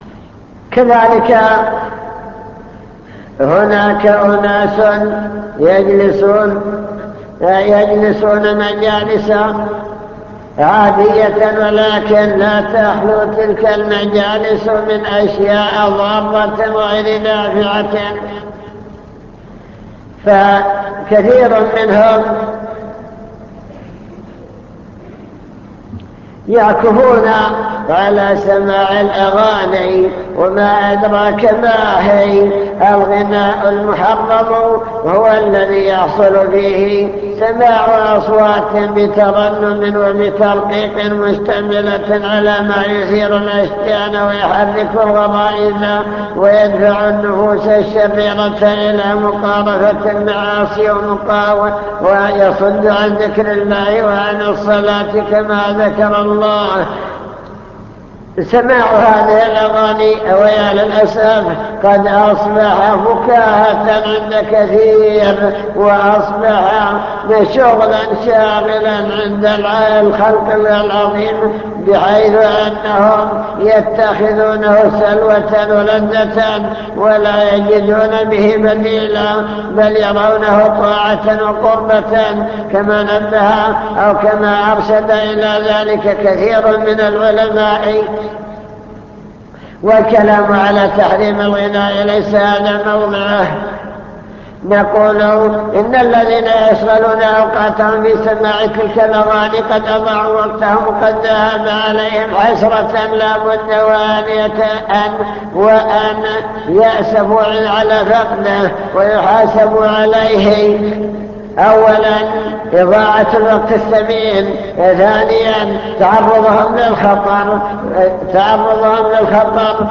المكتبة الصوتية  تسجيلات - محاضرات ودروس  محاضرة بعنوان الشباب والفراغ التحذير من إضاعة الوقت